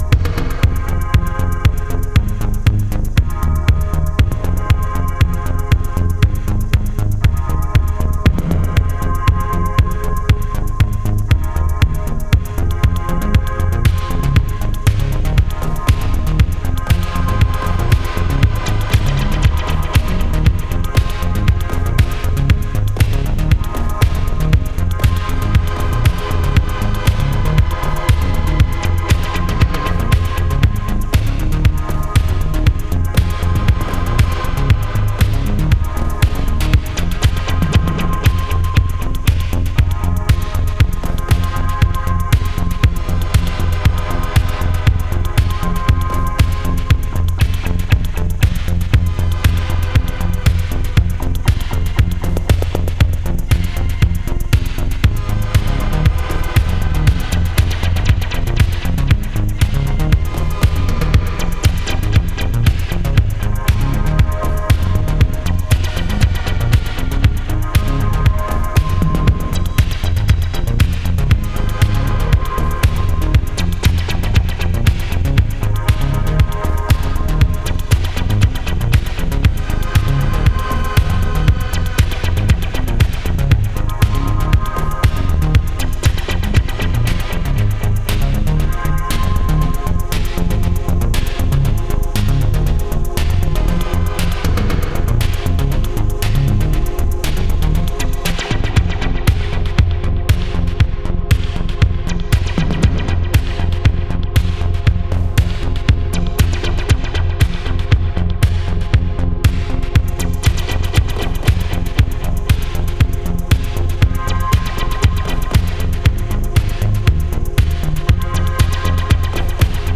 wave tainted electronics